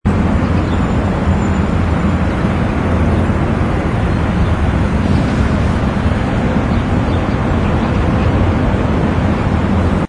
ambience_cityscape_heavy.wav